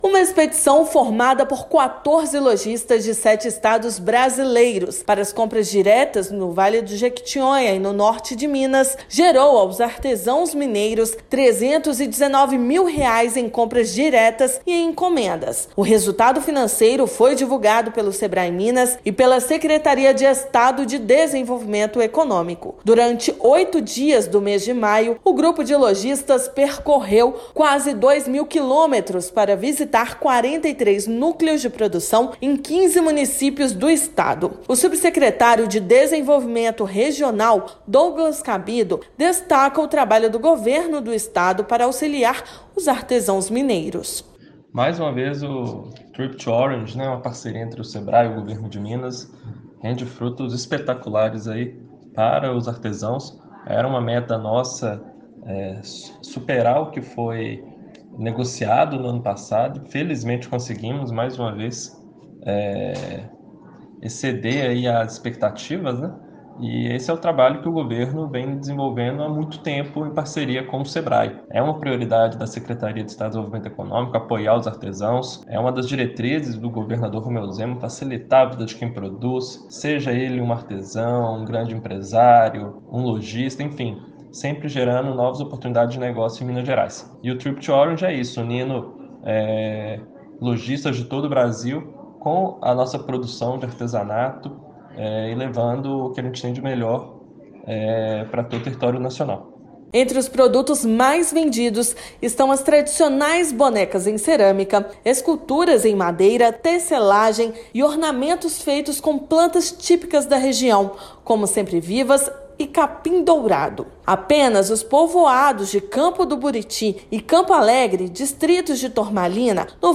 Iniciativa possibilitou a aproximação entre lojistas e artesãos, além da valorização da identidade e origem do artesanato produzido nas regiões mineiras. Ouça matéria de rádio.